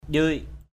/ʄɯɪ:ʔ/ (d.) rong, rêu = algues. njâc ndem di batau W;C Q# d} bt~@ rêu bám vào đá. ikan jiong di kraong kayua njâc (tng.) ikN _j`U d} _k” ky&%...